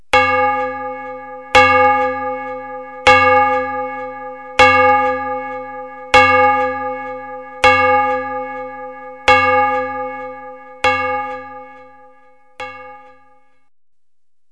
Бьют в колокол скачать бесплатно